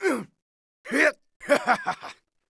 fishing_catch_v.wav